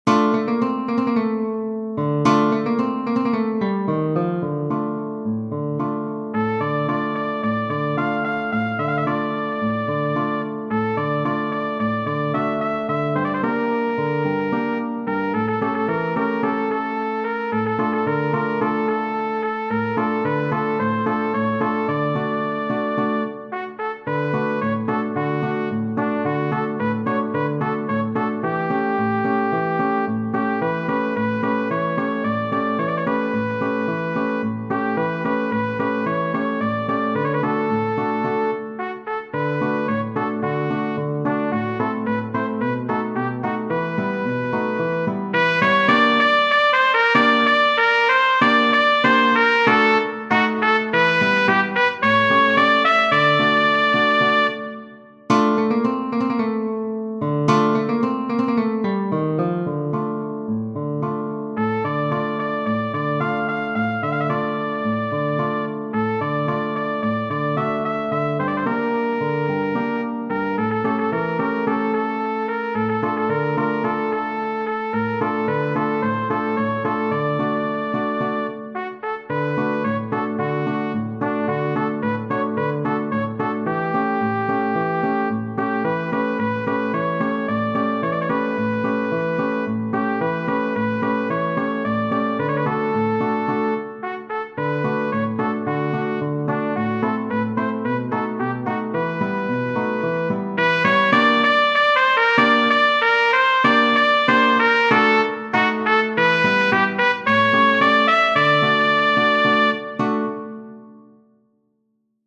Genere: Napoletane